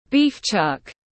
Thịt nạc vai bò tiếng anh gọi là beef chuck, phiên âm tiếng anh đọc là /biːf ʧʌk/
Beef chuck /biːf ʧʌk/